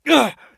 pain_3.ogg